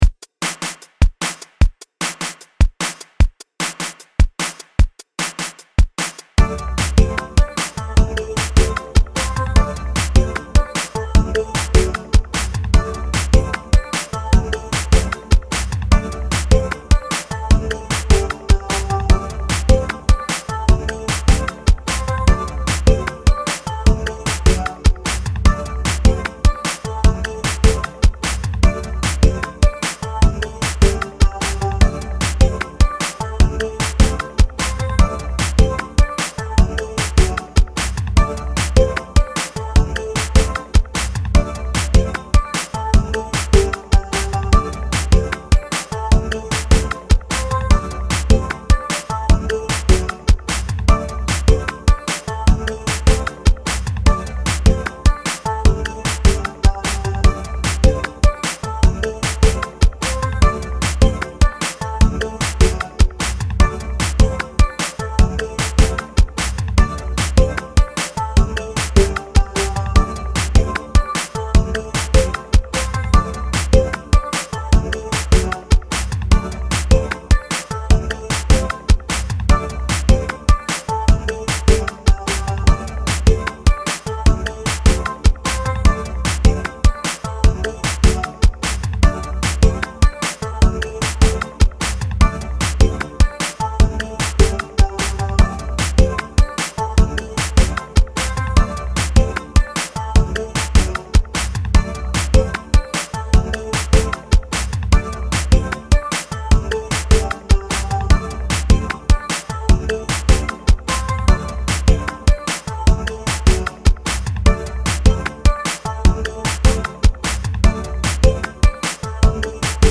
タイトル通り準備中っぽい曲です。